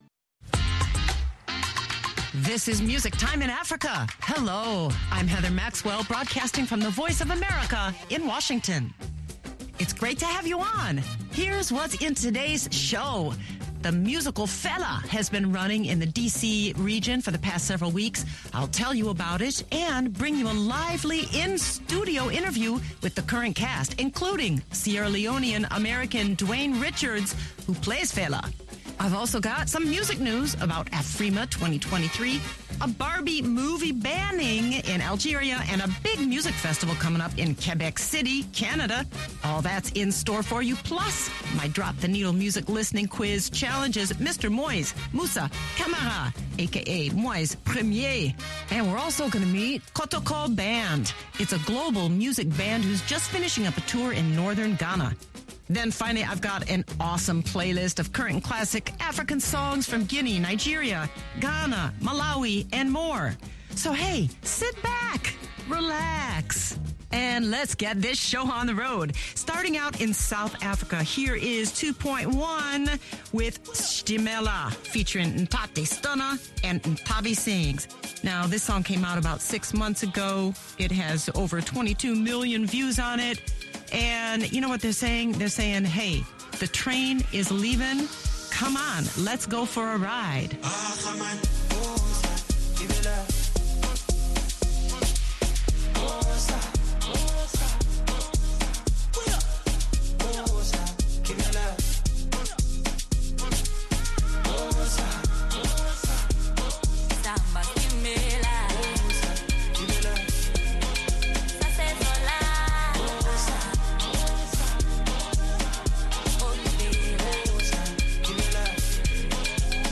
in studio at VOA